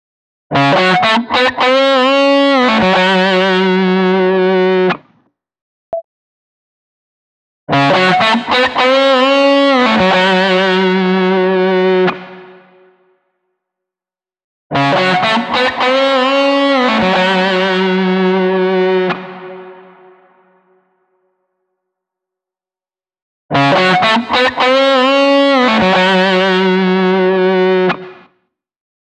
“OldSkool” [sic] reverb